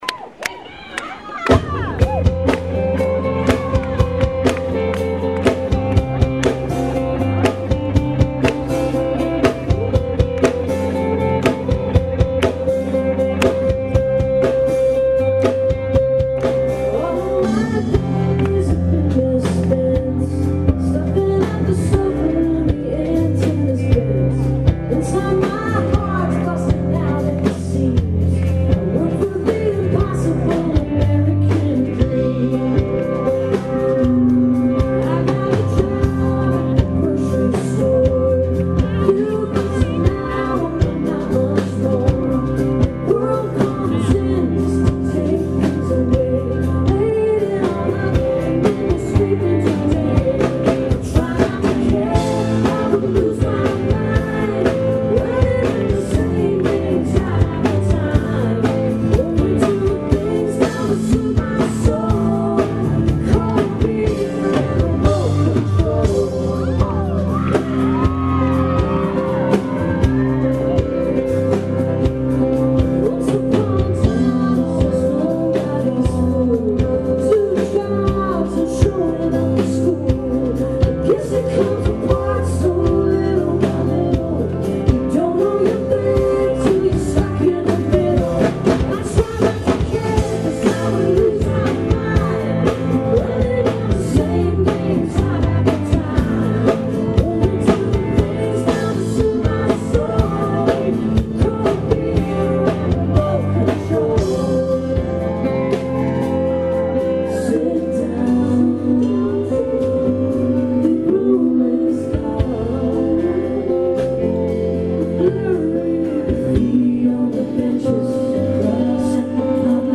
(band show)